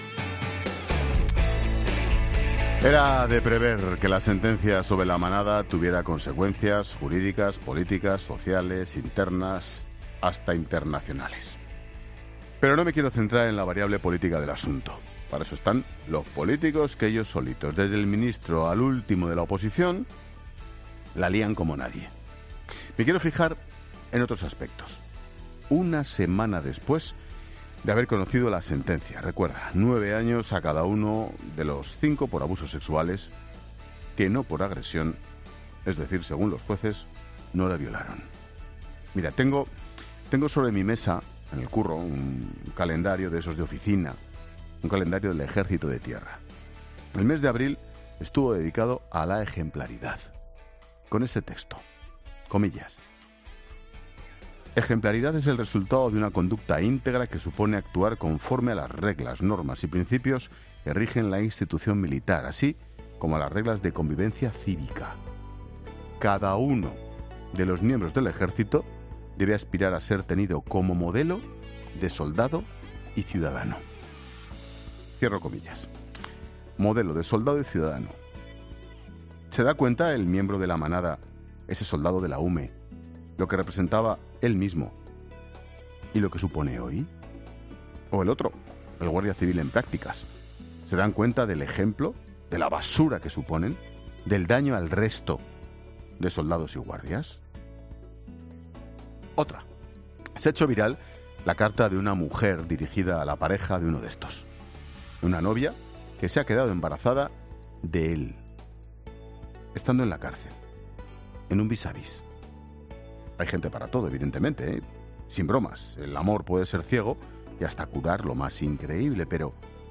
Monólogo de Expósito
El comentario de Ángel Expósito sobre las consecuencias de la sentencia a La Manada.